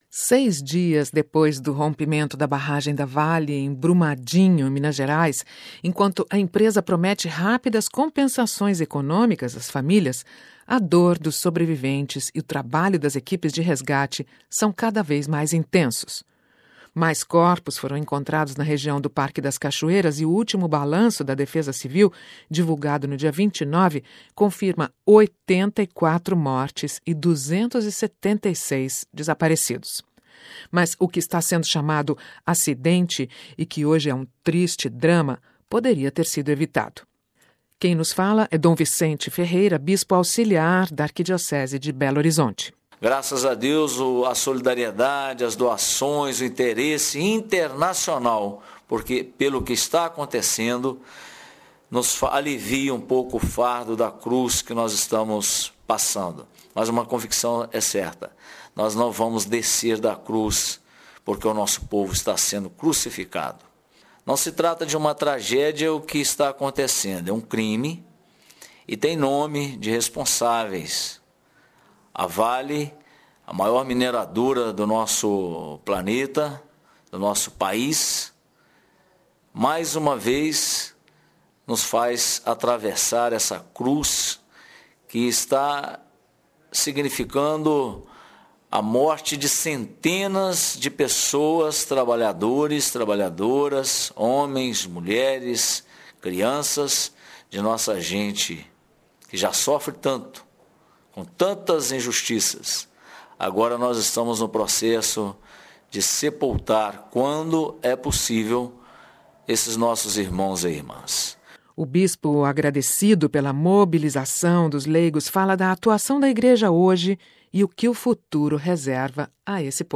Nós contatamos Dom Vicente Ferreira, bispo auxiliar da Arquidiocese de Belo Horizonte, presente em Brumadinho nestes dias.